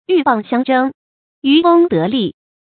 yù bàng xiāng zhēng，yú wēng dé lì
鹬蚌相争，渔翁得利发音
成语正音 鹬，不能读作“jú”；相，不能读作“xiànɡ”。